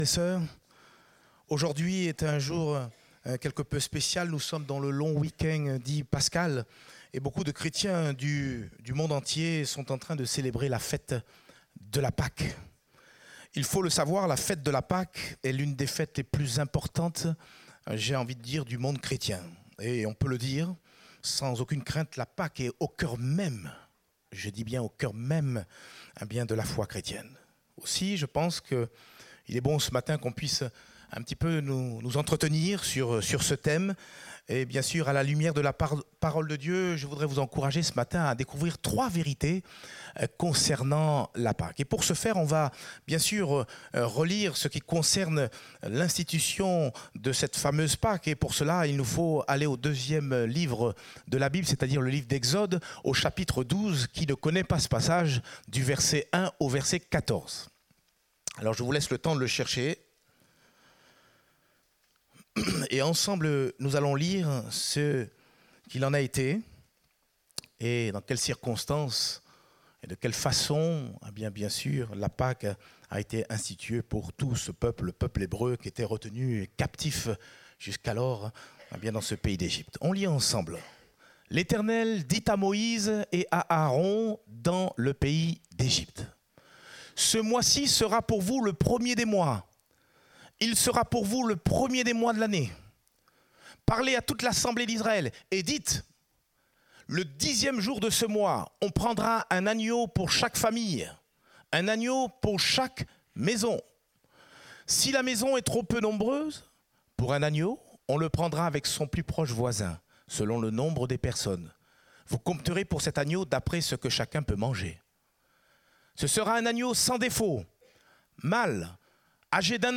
Date : 21 avril 2019 (Culte Dominical)